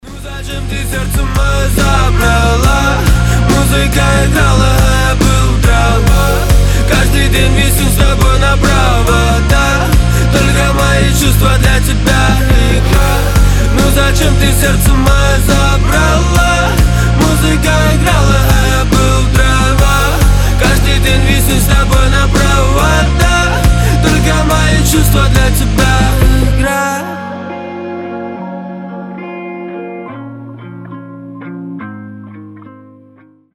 • Качество: 320, Stereo
громкие
красивый мужской голос